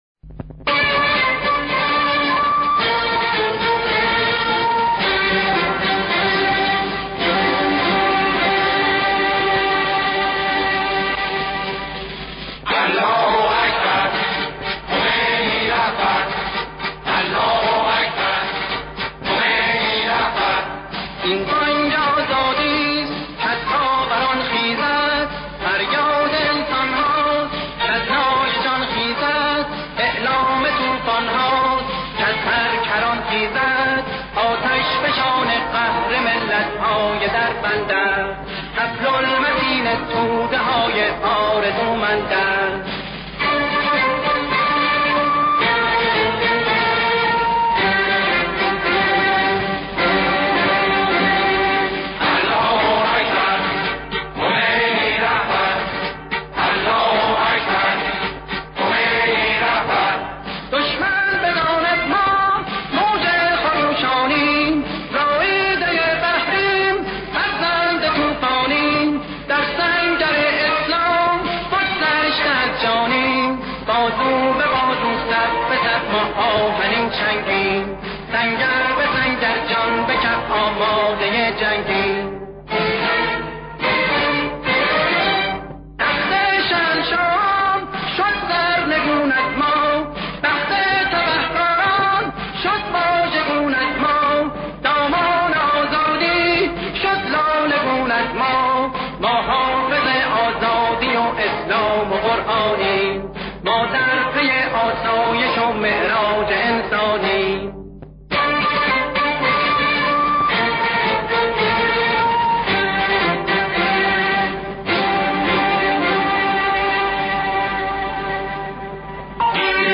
??آواهای حماسی??